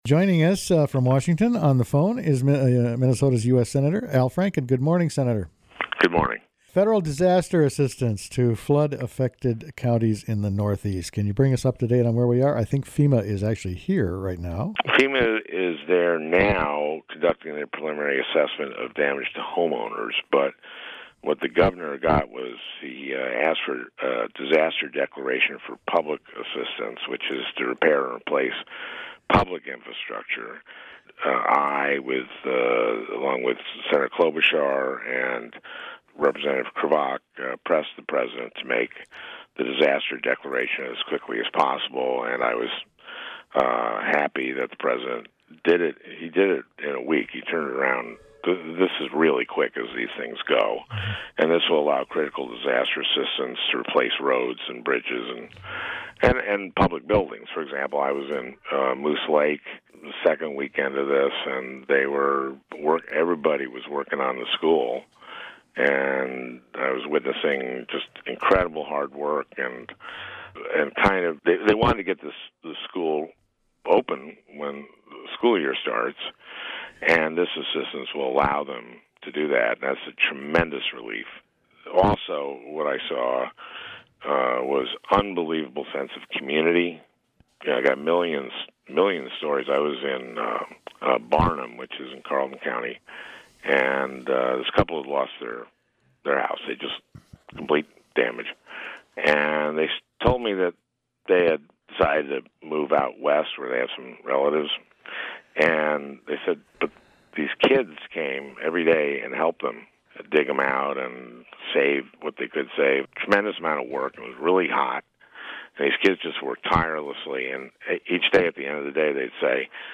Sen. Franken talks about FEMA flood support and the Affordable Care Act